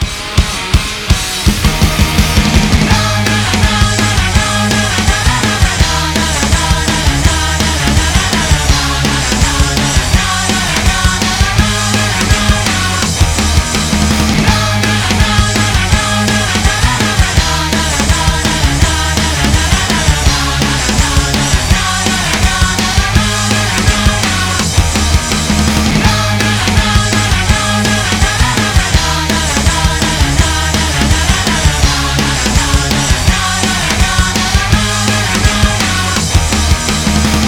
LE JINGLE